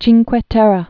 (chēngkwĕ tĕrrĕ)